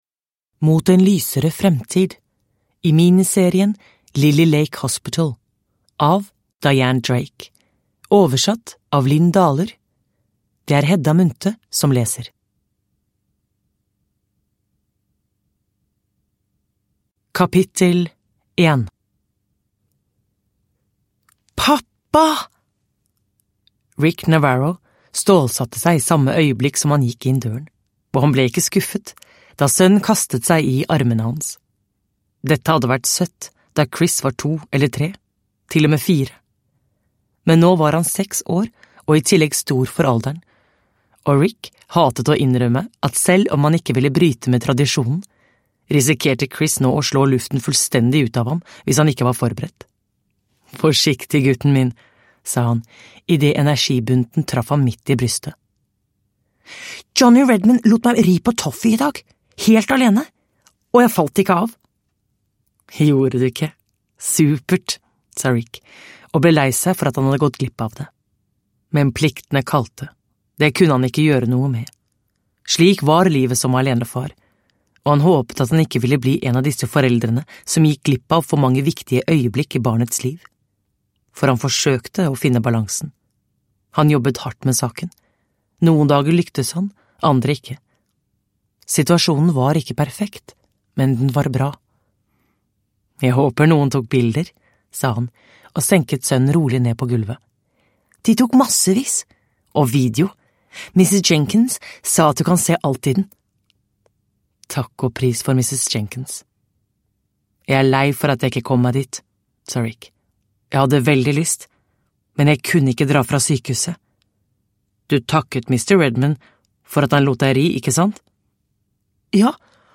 Mot en lysere fremtid – Ljudbok – Laddas ner